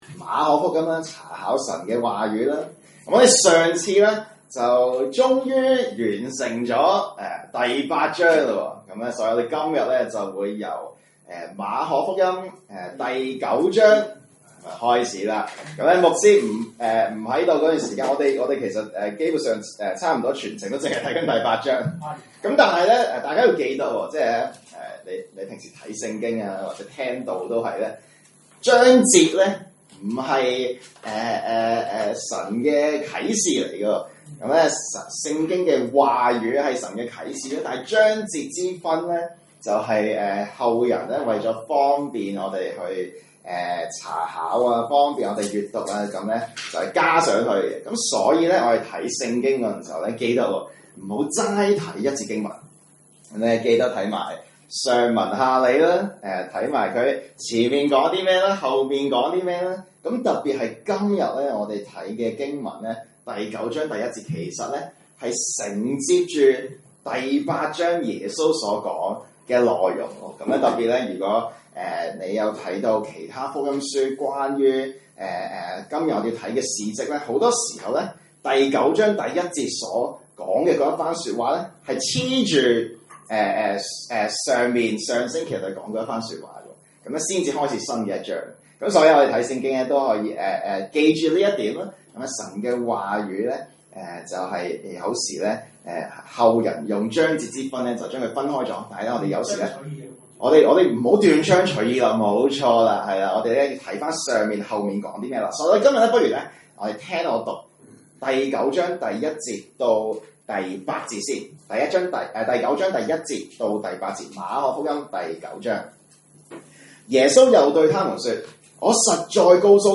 證道信息
來自講道系列 "查經班：馬可福音"